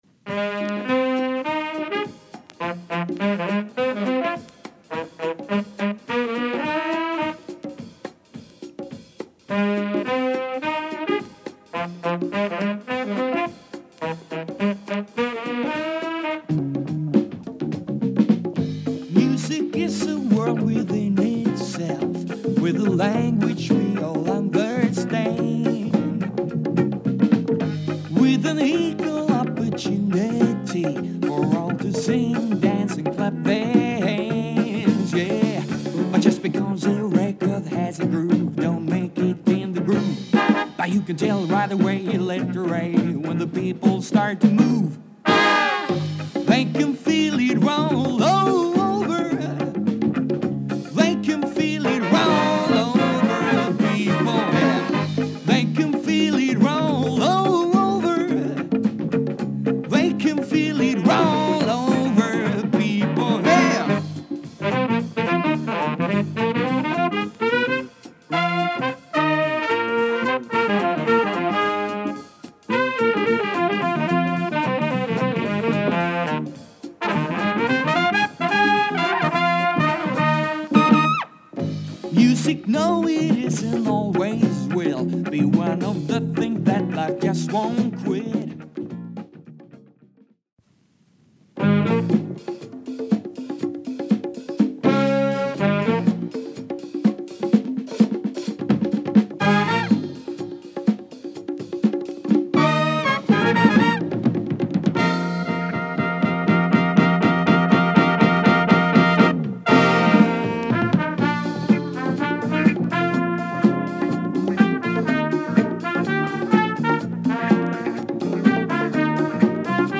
SOUL JAZZ マイナー盤 SWEDEN Orig.
パーカッシヴでディスコライクなリズムも小気味良いソウルフルな
SWEDEN オリジナル STEREO LP
再生もノイズ感大変少なく十分概ね良好です。